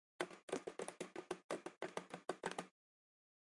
iphone » Mobile vibration
描述：A phone is ringing
标签： call cellphone vibration mobile phone ringing
声道立体声